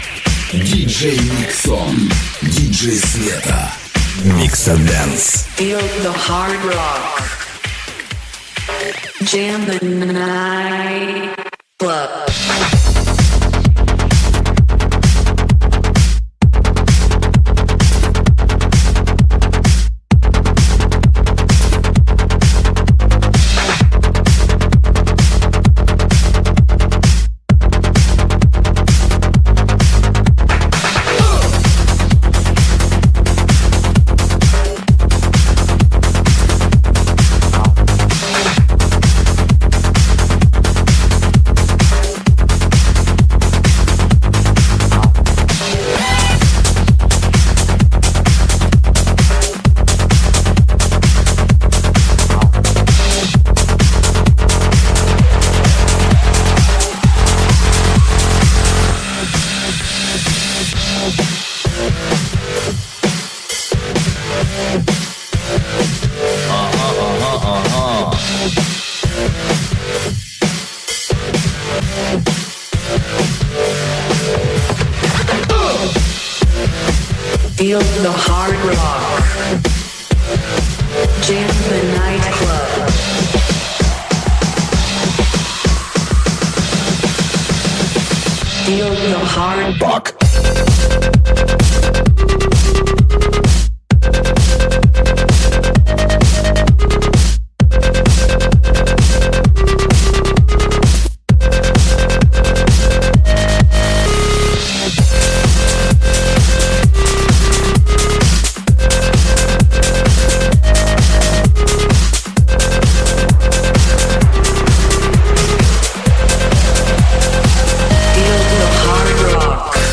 Назад в Клубная
Електро